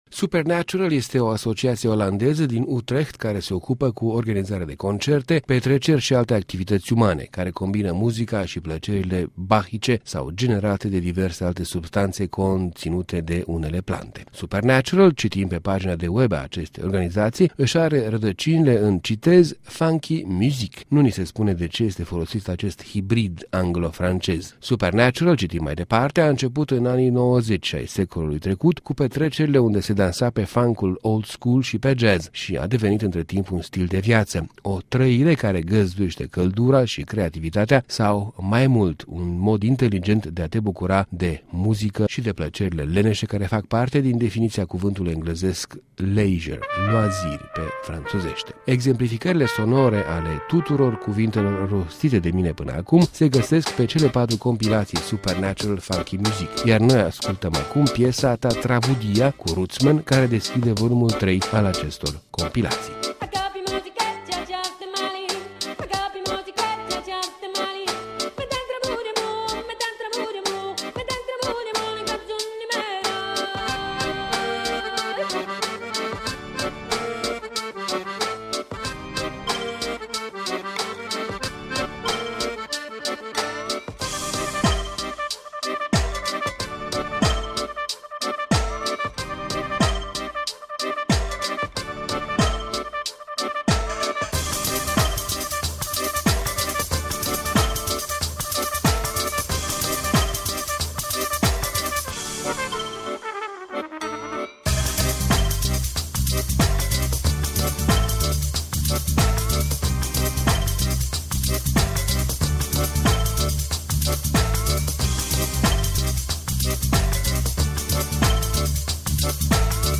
Funk old school & jazz.